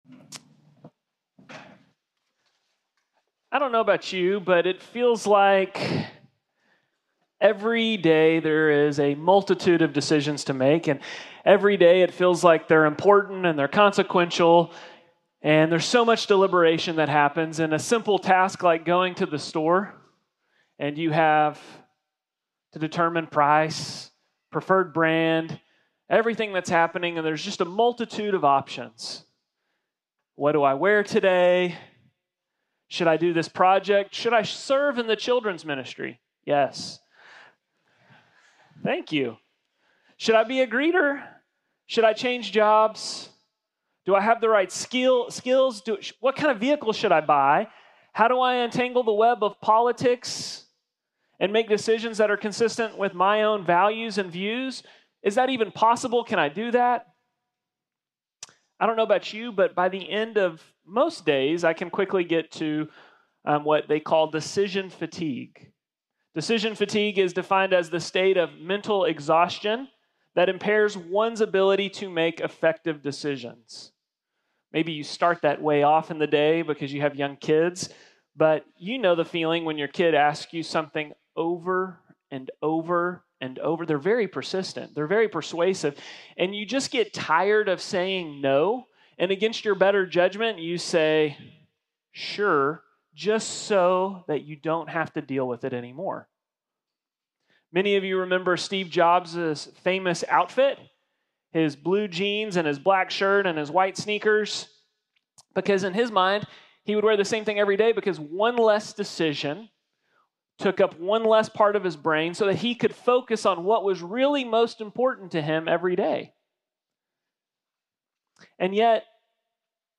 The Narrow Way: Decisions (Combined service at 10 am on 8/10/2025)